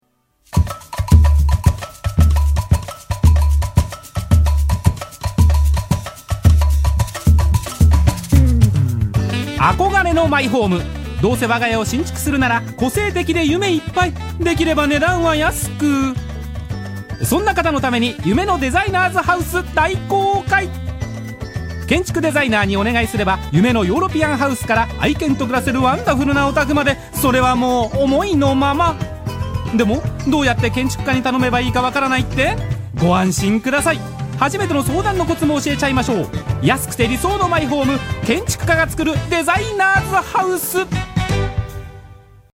日本語 男性